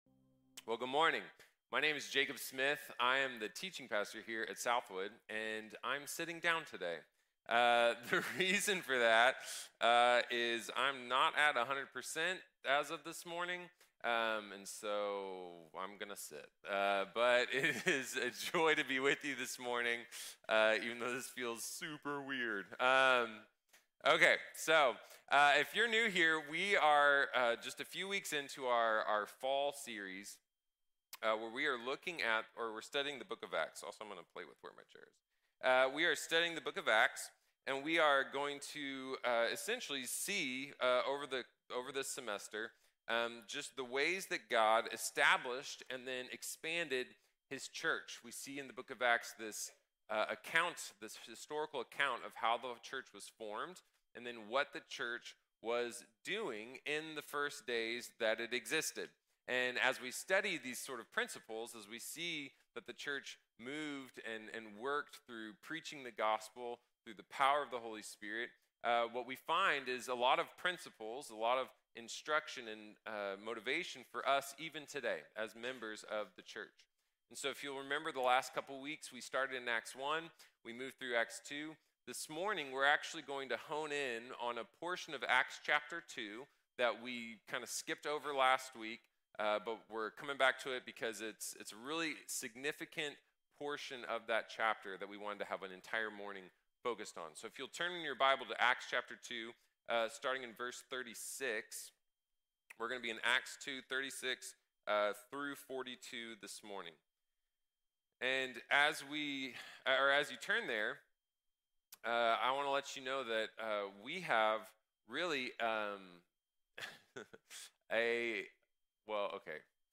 Revelation and Response | Sermon | Grace Bible Church